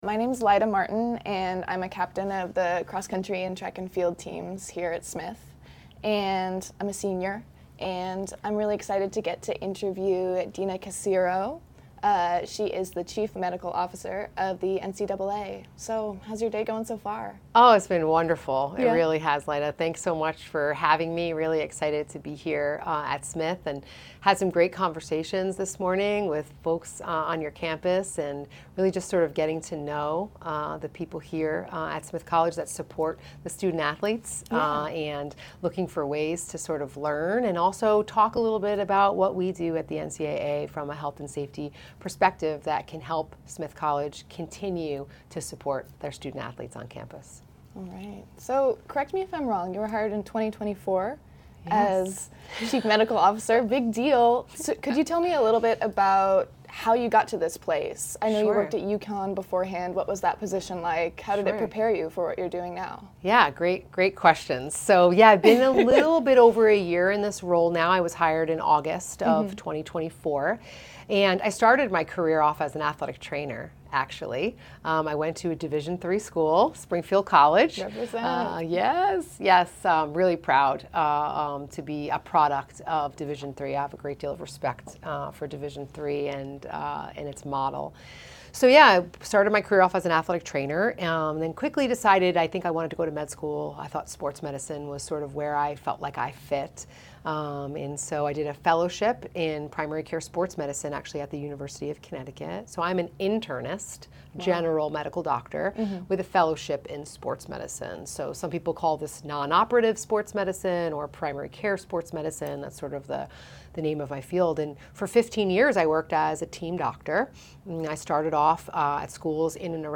2025 In this special interview